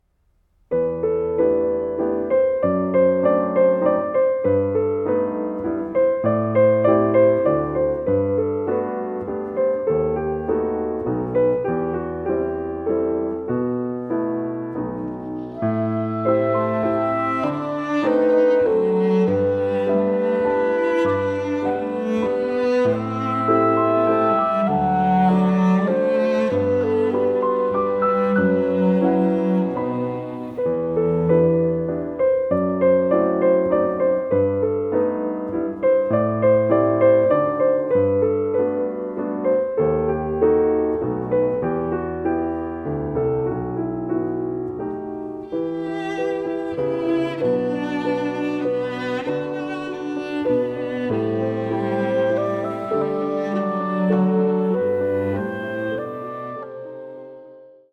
like a romantic and nostalgic musical journey.